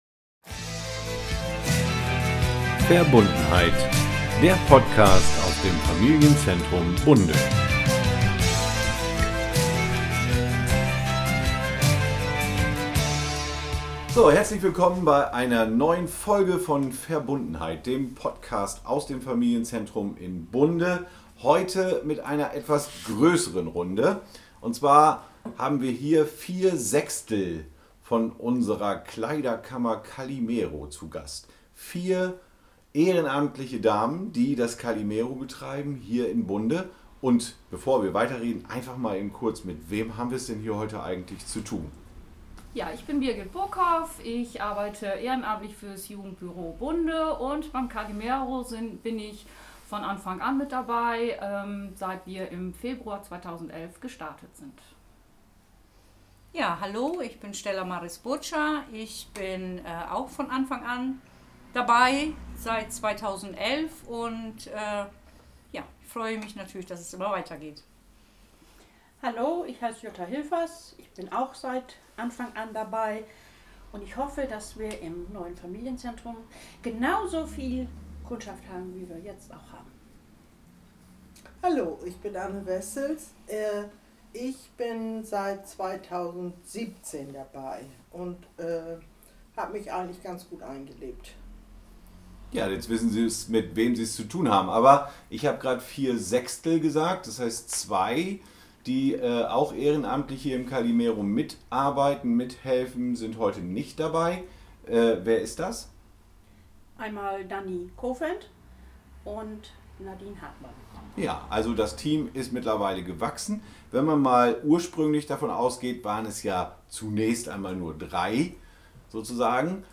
Interview mit dem ehrenamtlichen Team der Klamottenkiste Kallimero.